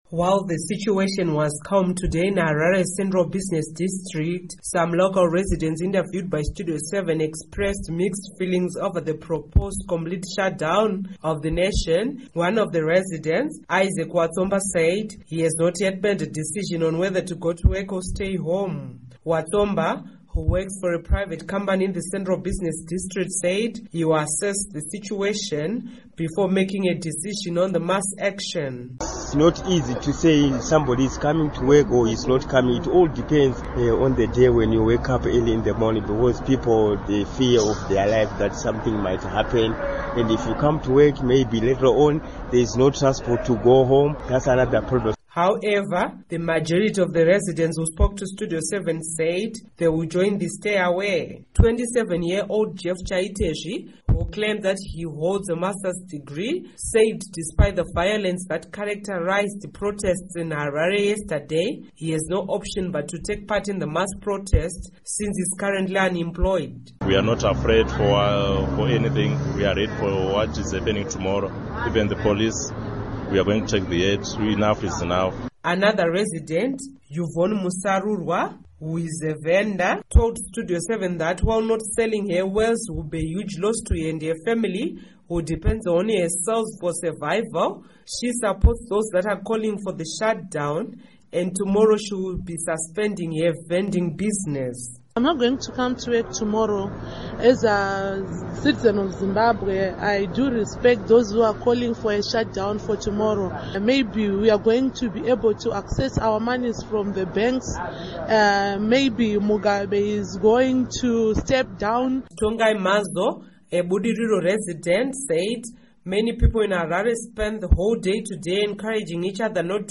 Report on Zimbabwe Protests